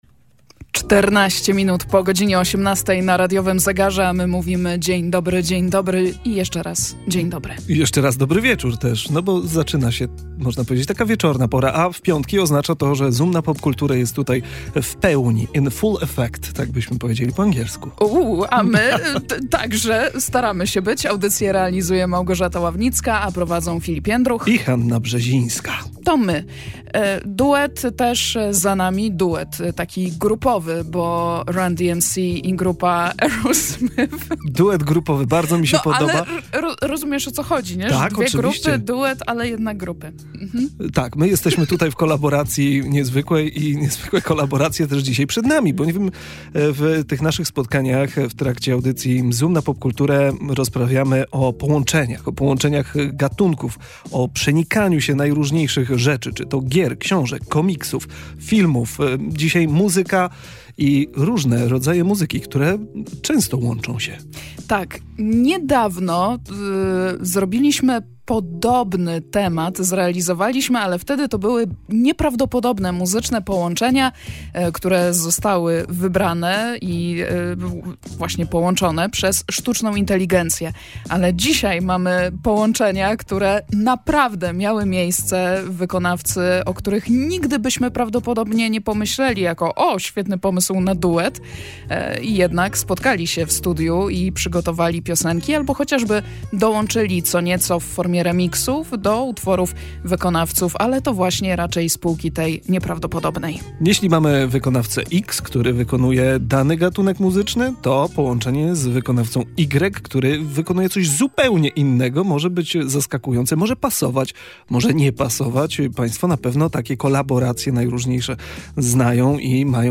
W audycji "Zoom na Popkulturę" rozmawialiśmy o muzycznych eksperymentach, nieoczywistych połączeniach gatunkowych i wpływie unikalnych kolaboracji na popkulturę.